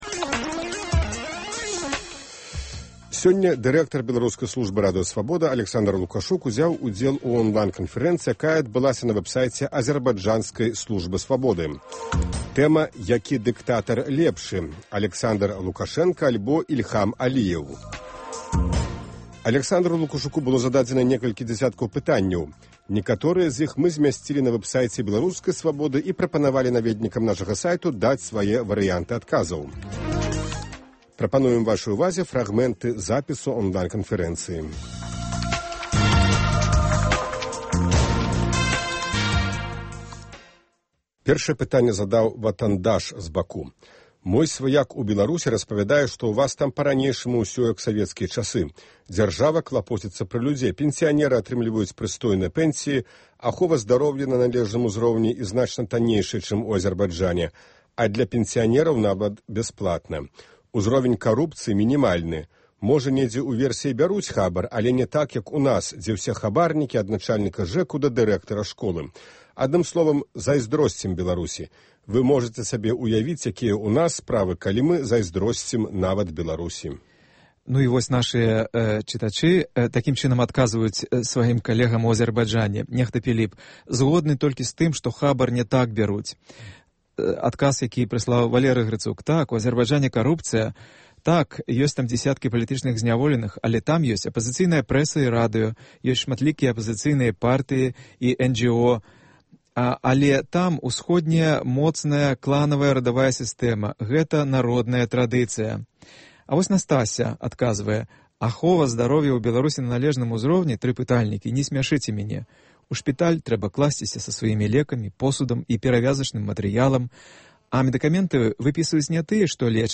Онлайн- канфэрэнцыя
Запіс онлайн-канфэрэнцыі